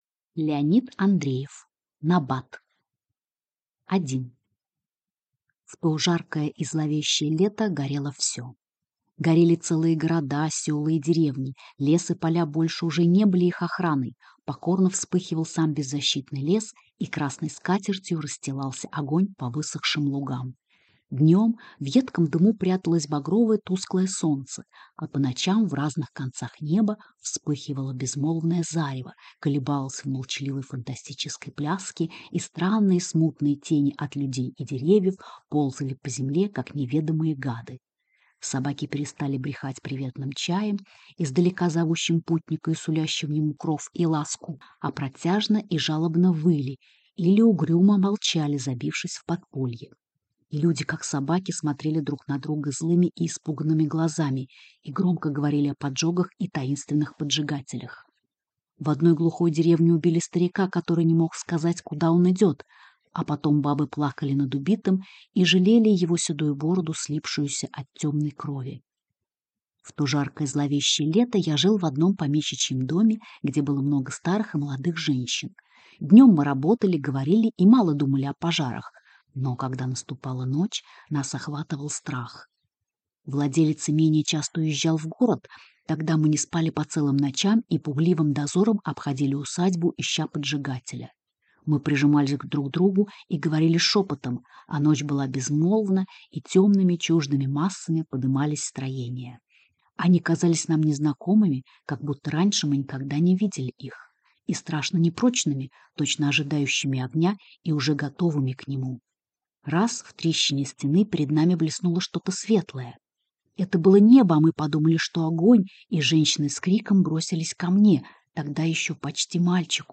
Аудиокнига Набат | Библиотека аудиокниг